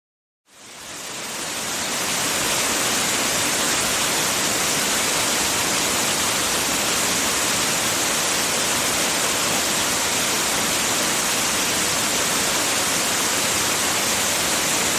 River Rapid
River Rapid is a free nature sound effect available for download in MP3 format.
604_river_rapid.mp3